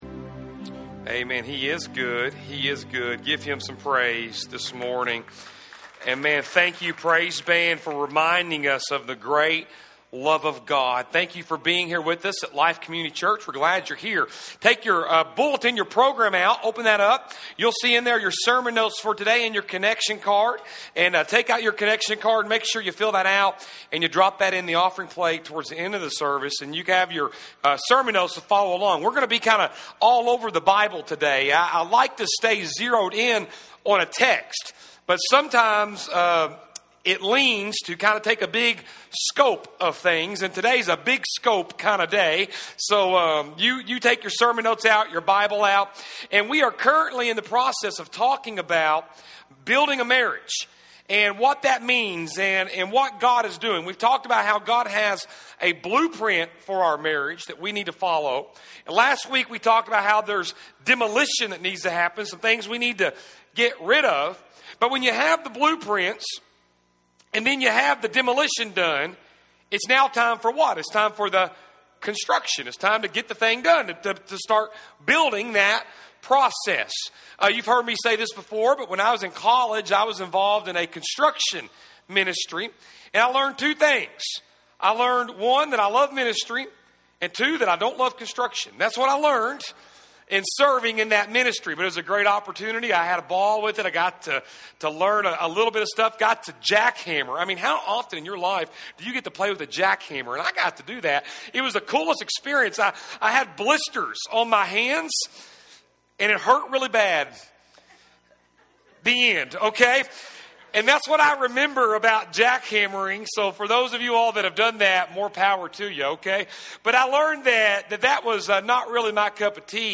February 28, 2016 Building a Marriage- Construction Service Type: Sunday AM | Third message in the series “Building a Marriage”.